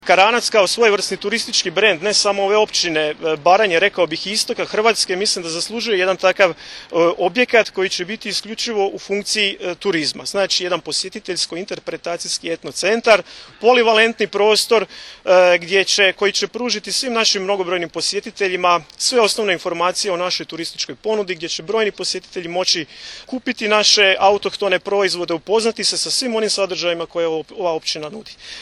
Načelnik Kramarić je naglasio kako će ovaj centar igrati ključnu ulogu u promociji lokalne kulture i tradicije, a posebno u privlačenju posjetitelja zainteresiranih za autentična baranjska iskustva.